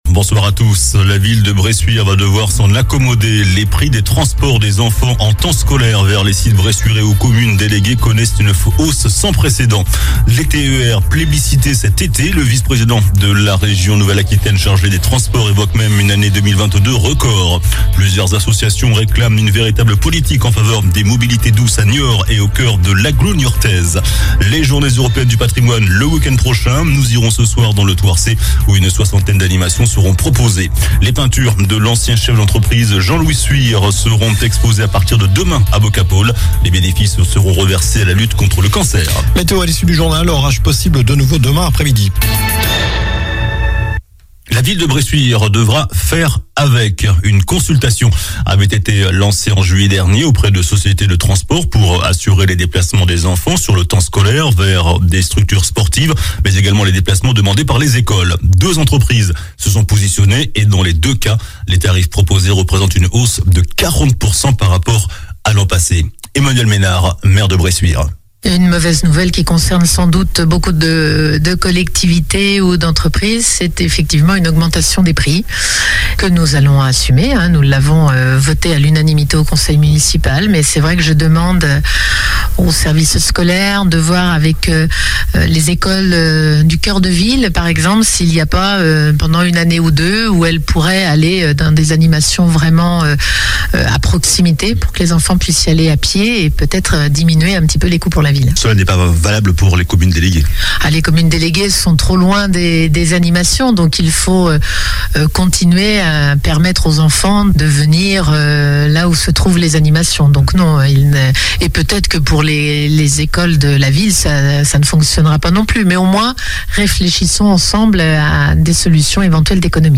JOURNAL DU MARDI 13 SEPTEMBRE ( SOIR )